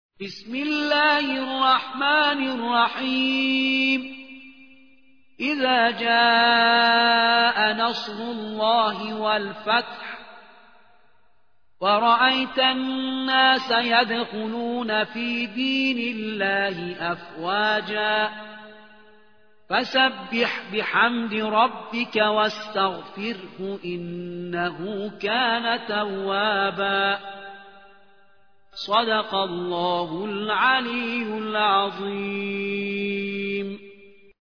110. سورة النصر / القارئ